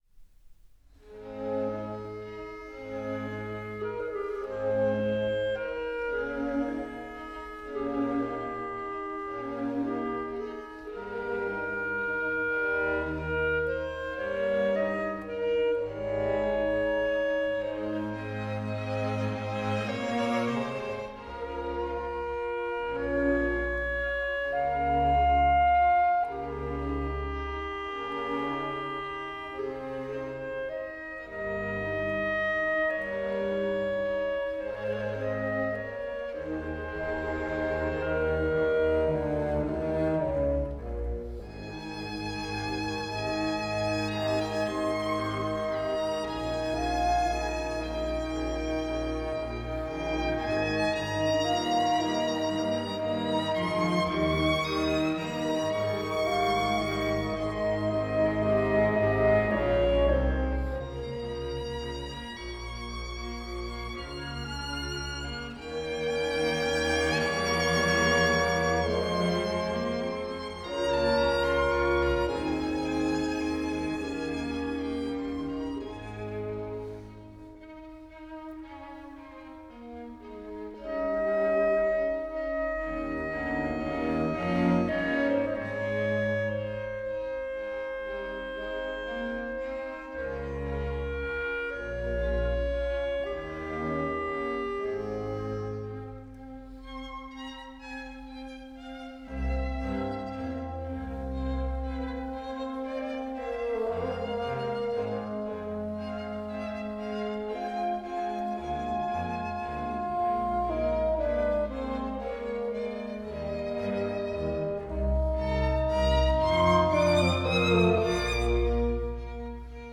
Beethoven, Septett für Violine, Viola, Klarinette, Horn, Fagott, Violoncello und Kontrab, 2. Adagio cantabile